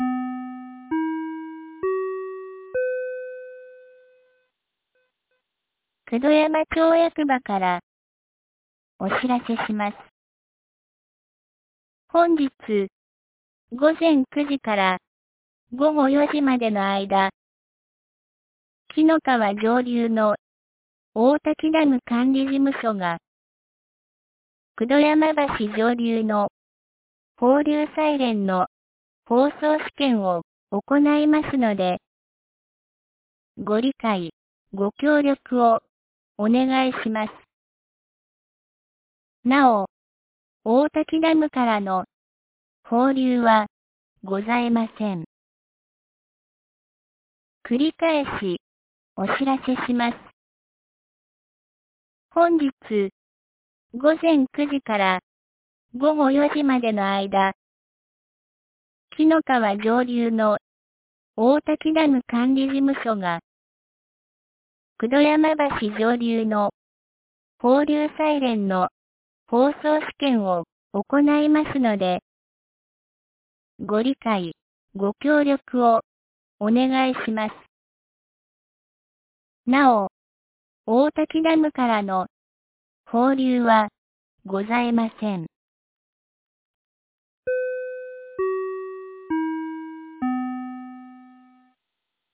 防災行政無線」カテゴリーアーカイブ
2025年05月08日 08時51分に、九度山町より紀ノ川沿線へ放送がありました。